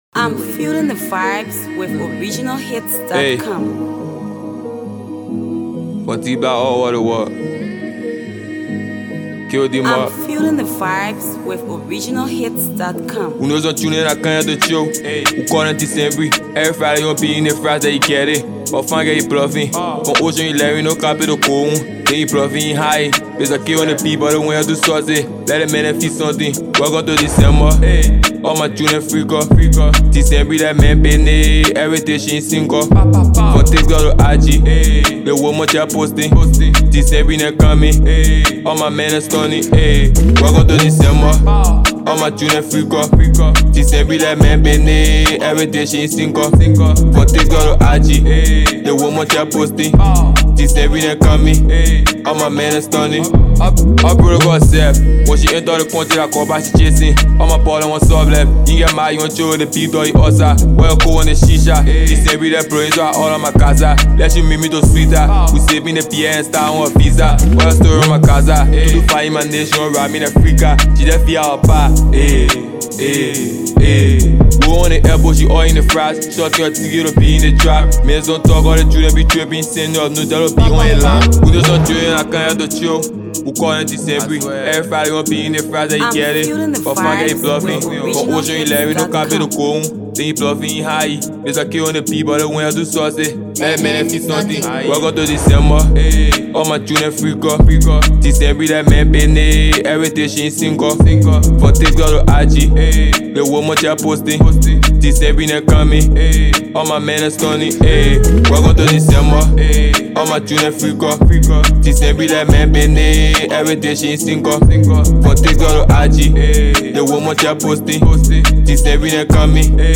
Talented Liberian trap artist